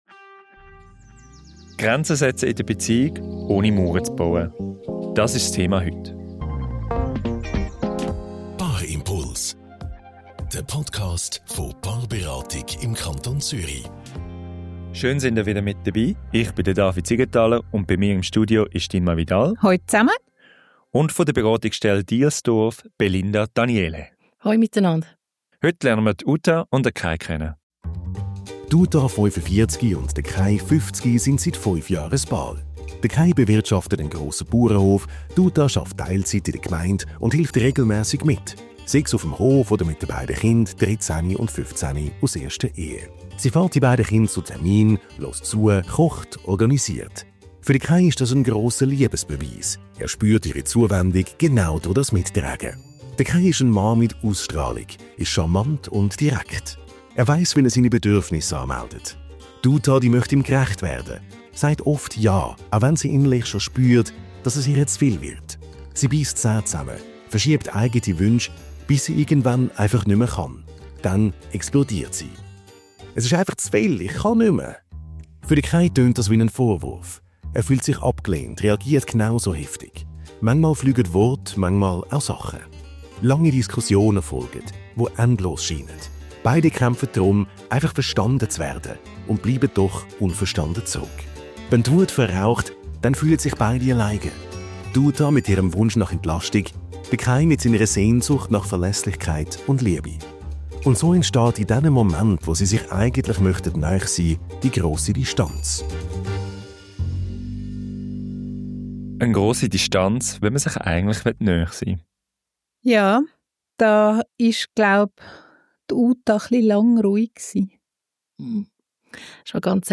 Wir sprechen darüber, wie Paare ihre Bedürfnisse deutlich machen können, warum Grenzen wichtig für gesunde Beziehungen sind und welche Wege es gibt, Standhaftigkeit mit Verbindung zu verbinden. Ein Gespräch darüber, wie Klarheit und Respekt zusammengehören – und wie Grenzen nicht trennen, sondern sogar neue Brücken bauen können.